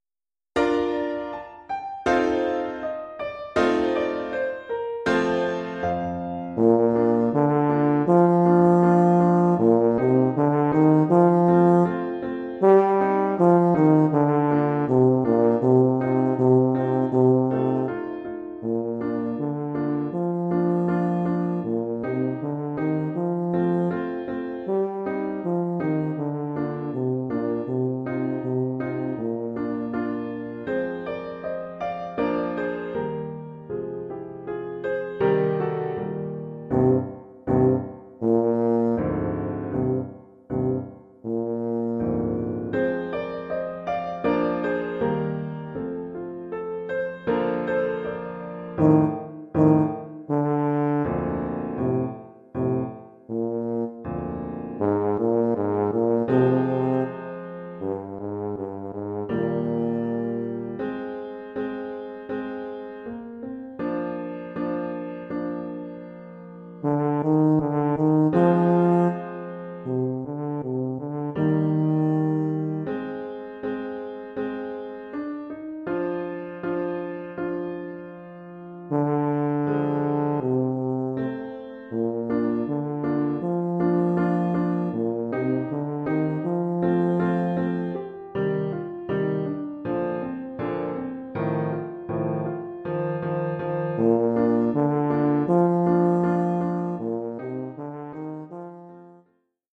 Formule instrumentale : Saxhorn basse/Tuba et piano
Oeuvre pour saxhorn basse / euphonium /
tuba et piano.
Niveau : débutant.